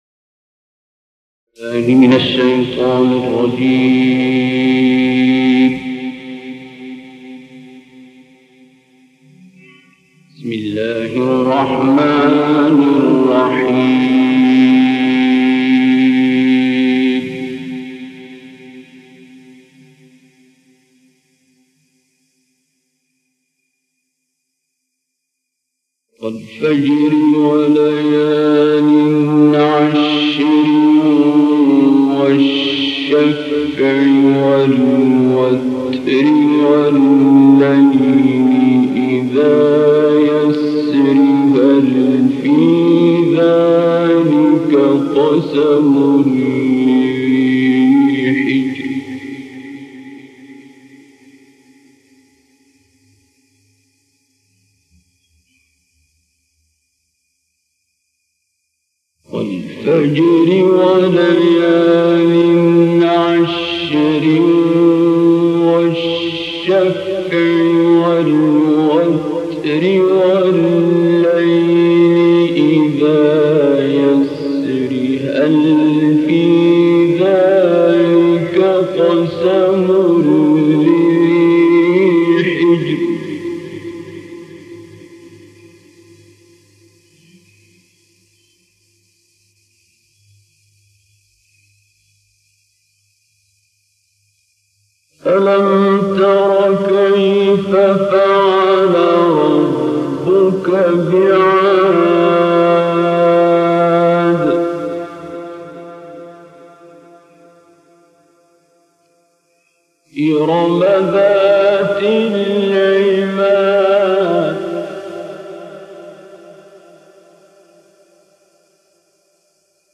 Аудио / Таляват суры «Фаджр» голосом Абдул Баситом